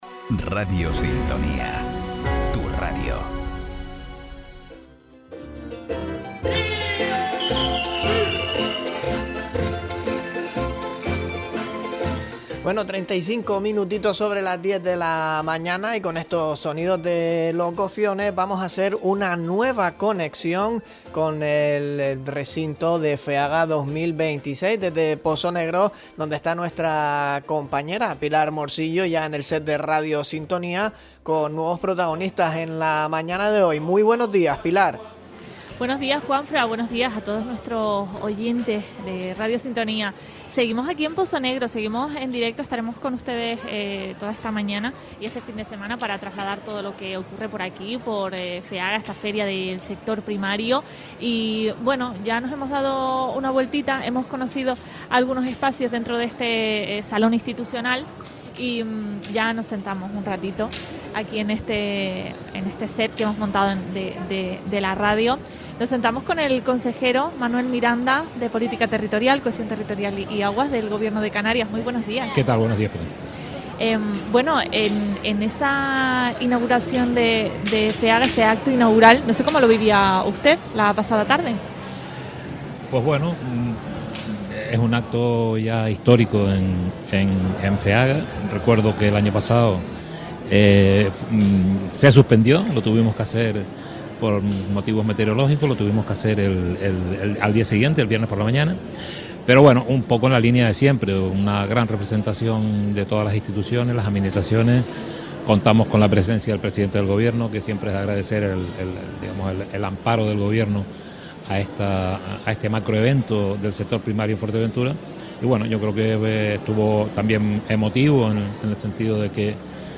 Entrevista a Manuel Miranda, consejero de Política Territorial del Gobierno de Canarias en Feaga 2026 - Radio Sintonía
Conocemos las impresiones de Manuel Miranda, Política Territorial, Cohesión Territorial y Aguas desde las instalaciones de Pozo Negro Deja un comentario